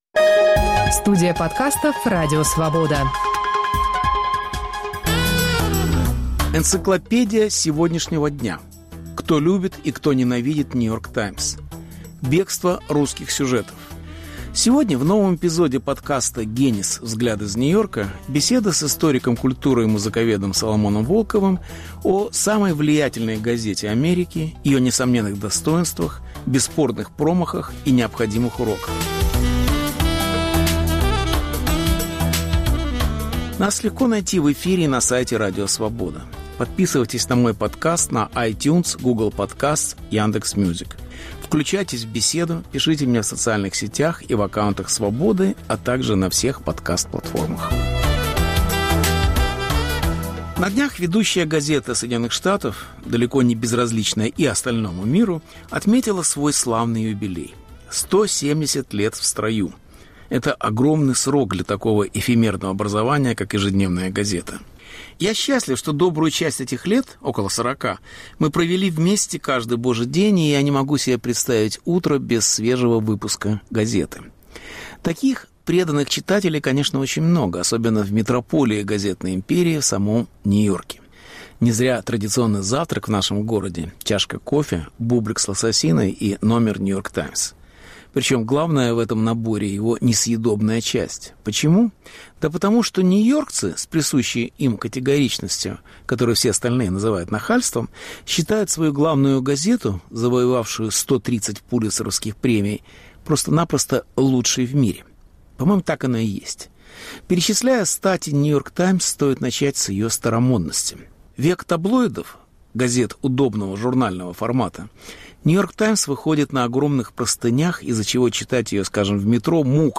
Беседа с Соломоном Волковым, приуроченная к 170-летию ведущей американской газеты. Повтор эфира от 20 сентября 2021 года.